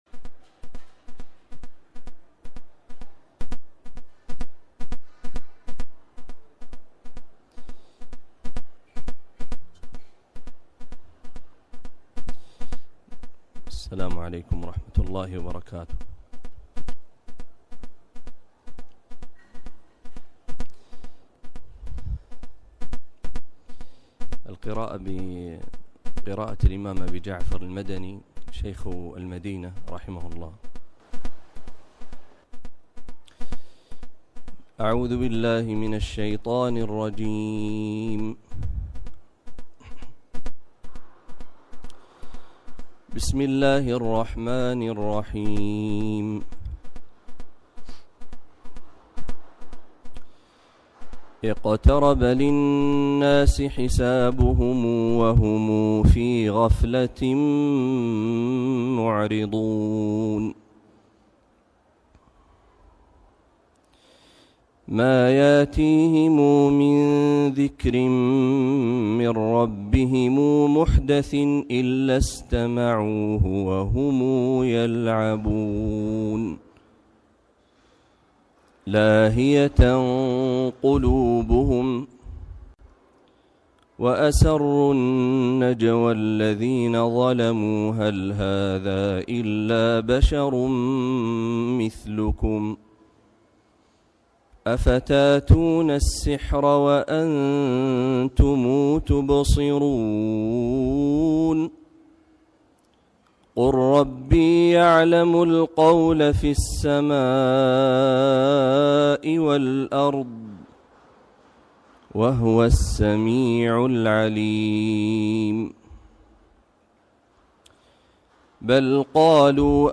إفتتاح اليوم الثاني من ندوة الفتوى في الحرمين الشريفين
بقراءة أبي جعفر المدني > ندوة الفتوى في الحرمين الشريفين > المزيد